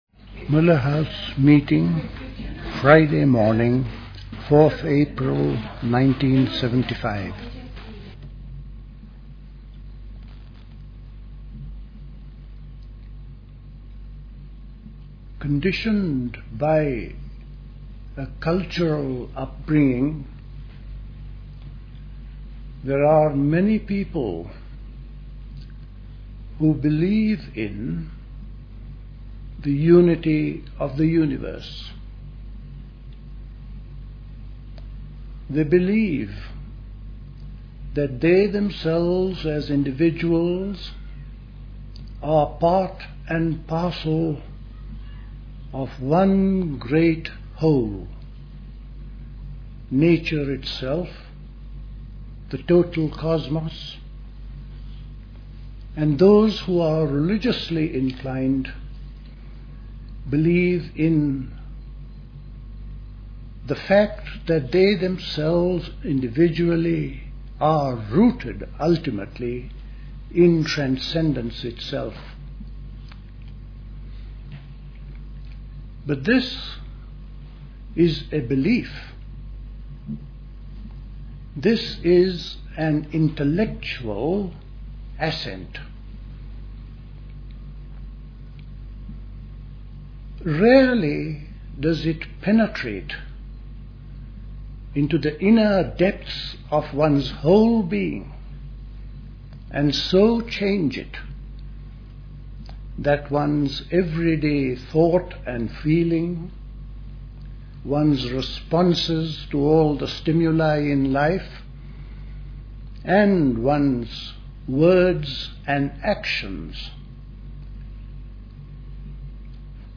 Recorded at the 1975 Elmau Spring School.